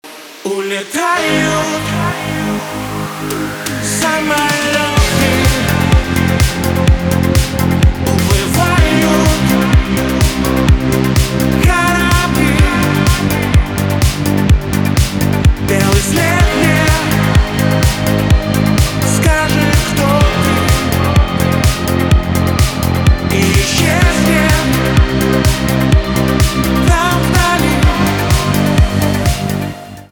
поп
грустные , битовые
печальные , чувственные